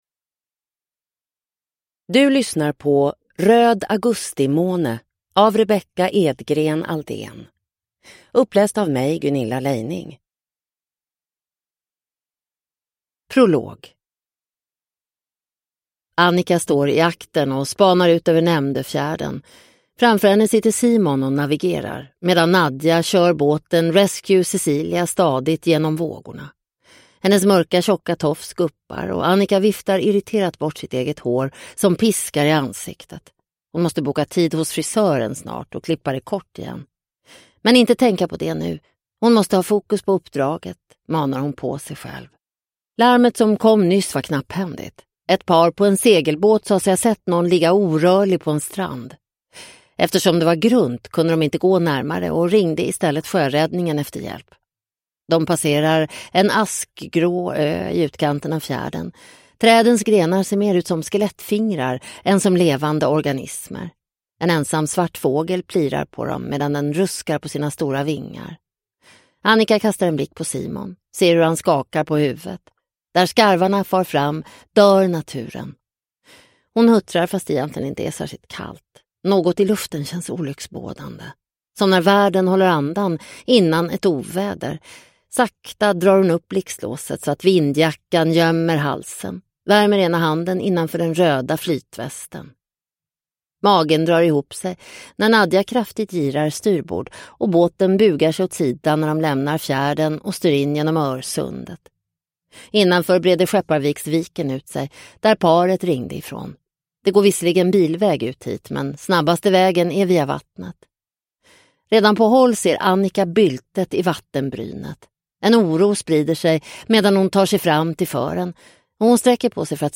Röd augustimåne – Ljudbok – Laddas ner